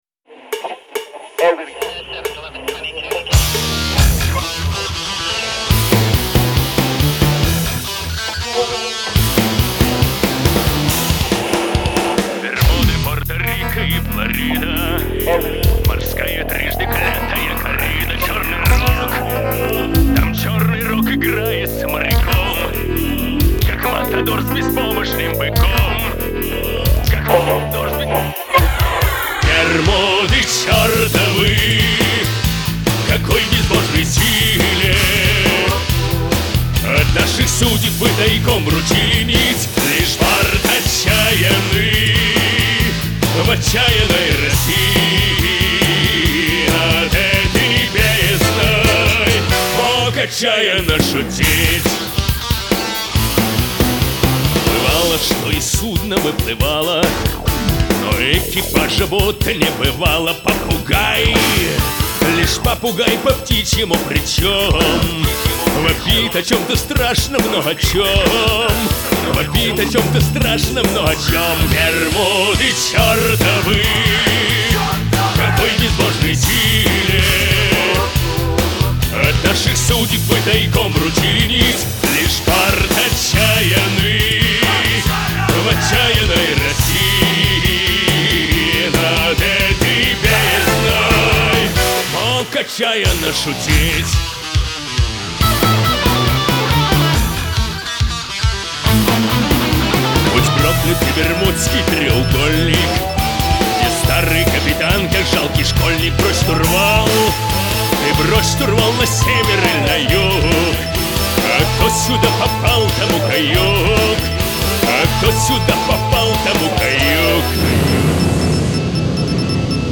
гусли, бэк-вокал.